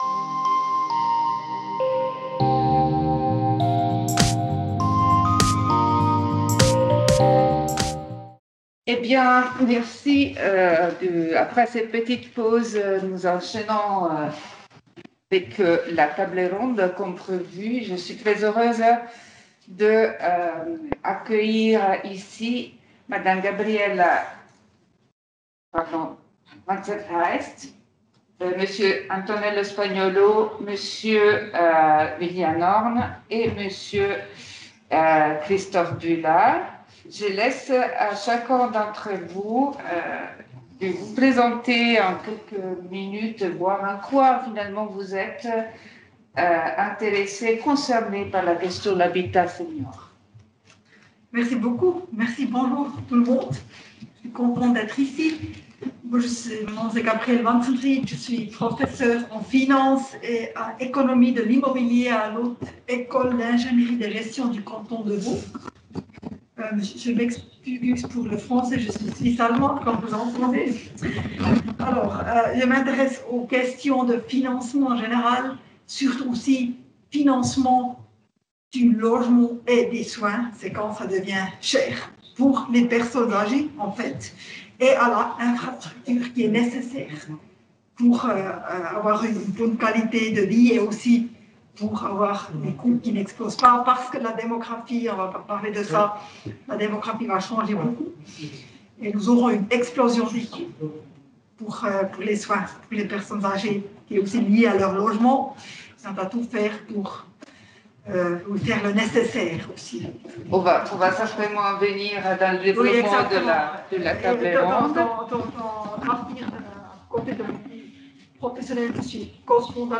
Table_ronde_audio.mp3